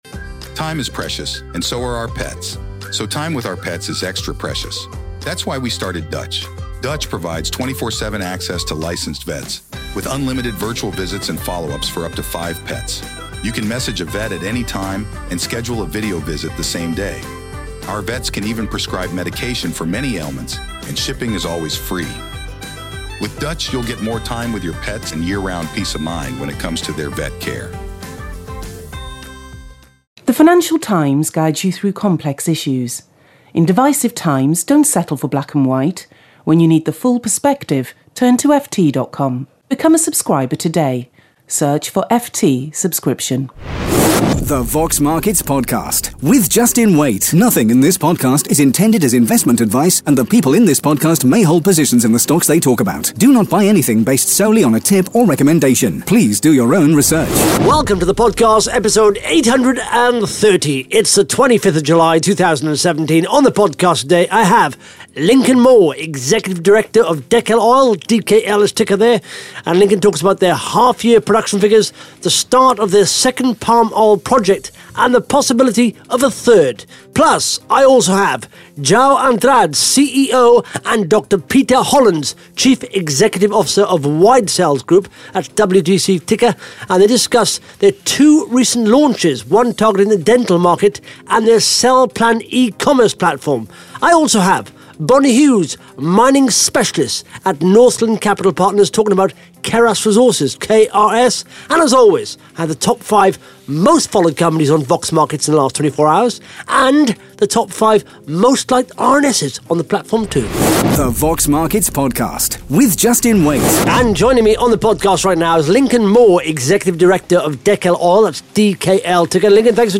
(Interview starts at 1 minute 20 seconds)